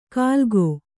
♪ kālgō